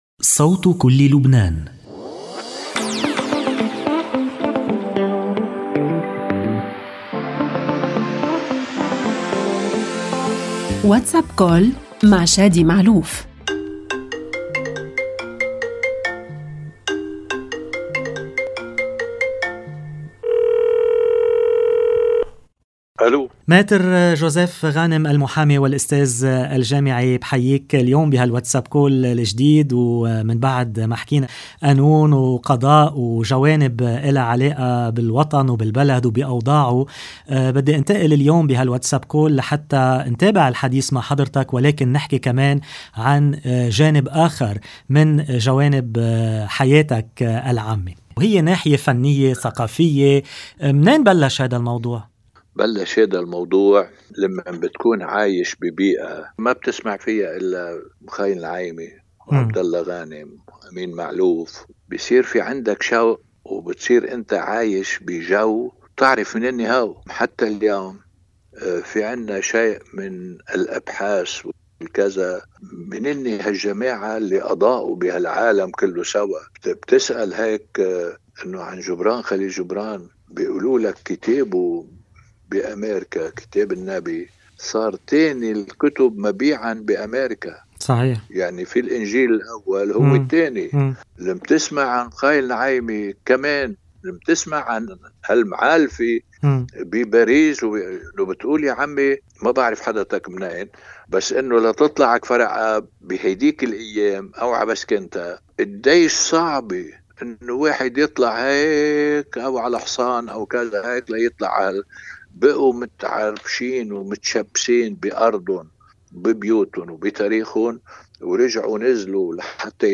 WhatsApp Call